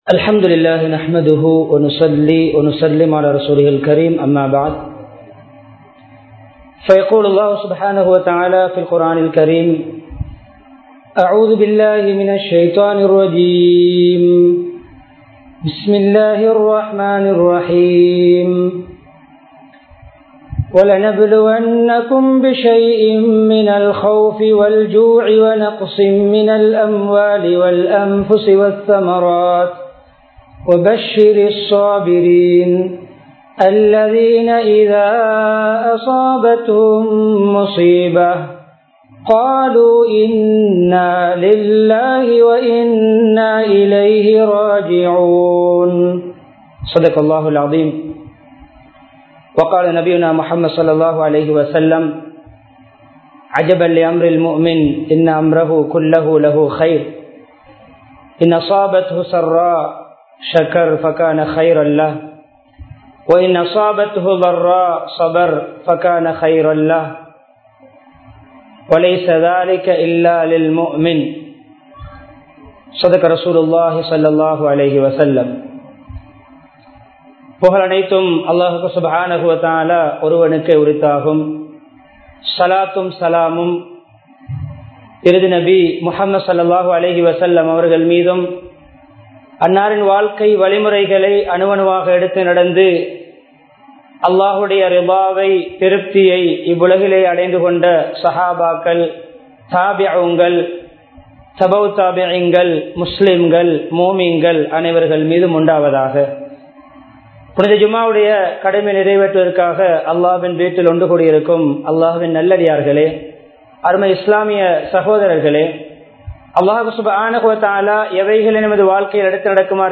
ஜனாஸாக்களை மதிப்போம் | Audio Bayans | All Ceylon Muslim Youth Community | Addalaichenai